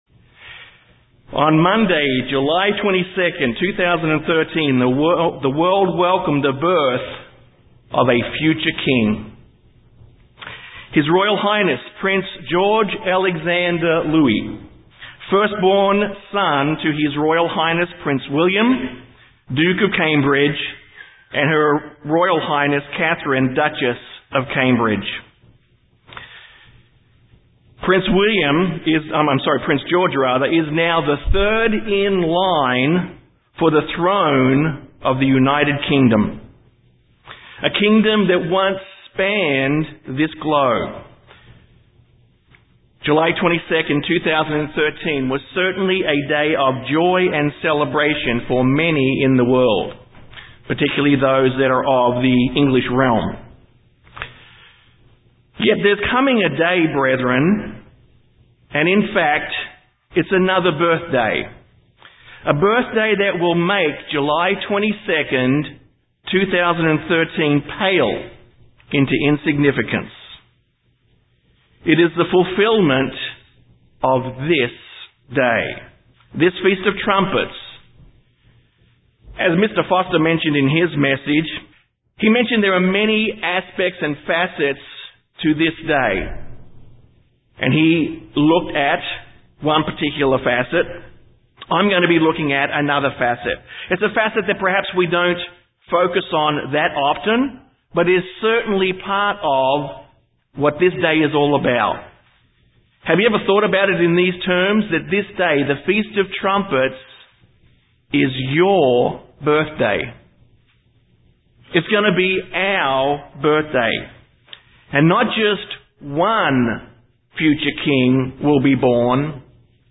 Given in San Antonio, TX
UCG Sermon Studying the bible?